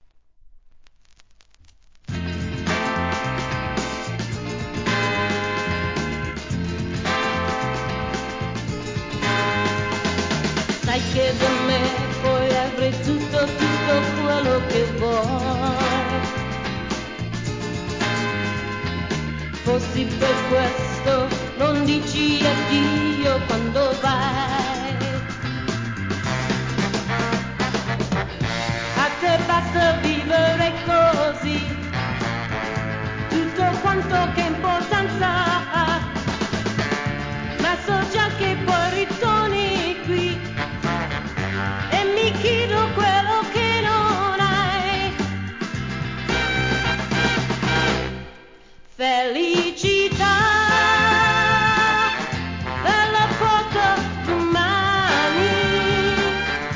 SOUL/FUNK/etc... 店舗 ただいま品切れ中です お気に入りに追加 イタリア、レアFUNK!!